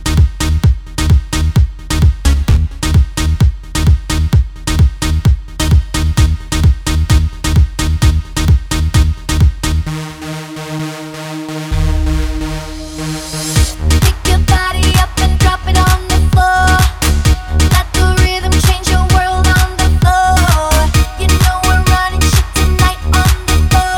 For Solo Female Pop (2000s) 3:48 Buy £1.50